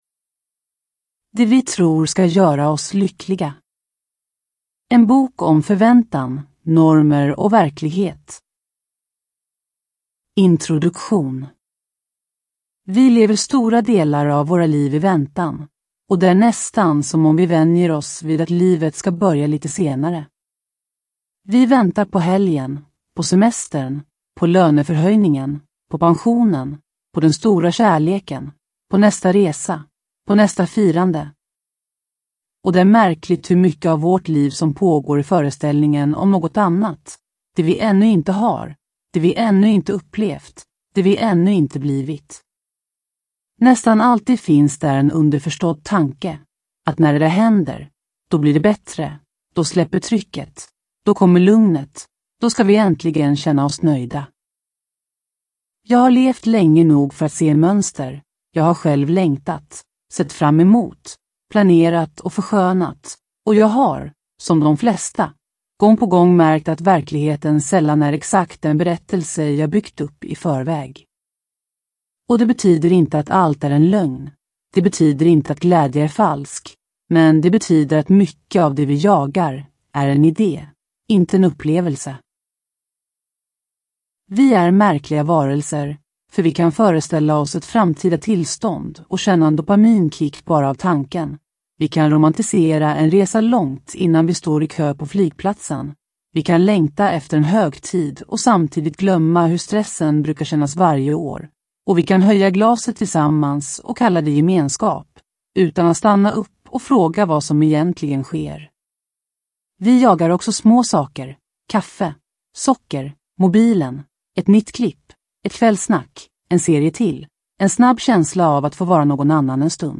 Det vi tror ska göra oss lyckliga – om förväntningar, längtan och verklighet – Ljudbok